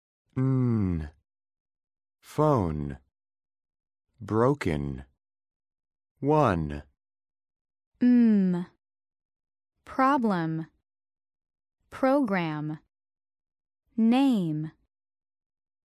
PRONUNCIATION: Saying final /n/ and /m/ sounds